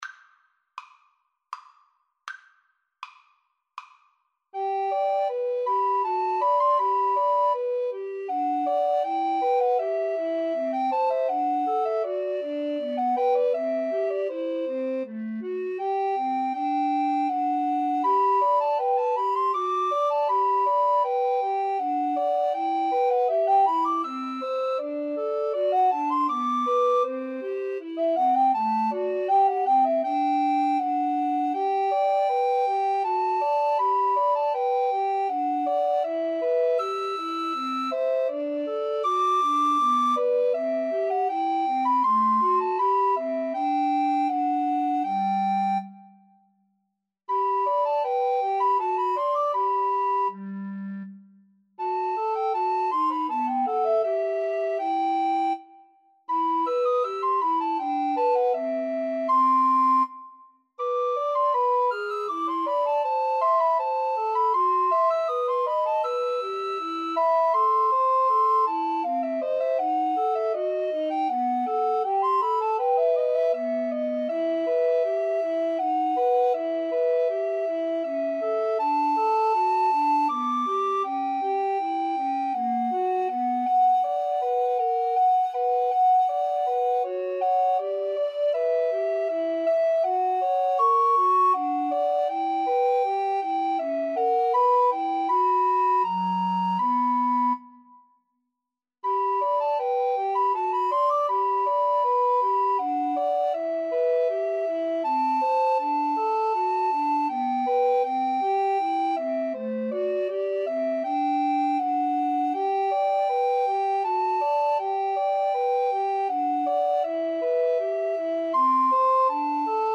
3/4 (View more 3/4 Music)
Andante
Classical (View more Classical Recorder Trio Music)